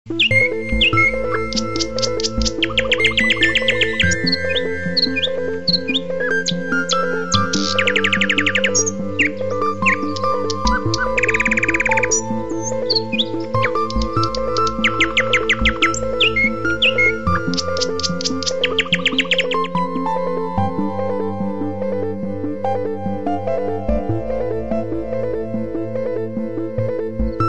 Birds Alarm Clock klingelton kostenlos
Kategorien: Wecktöne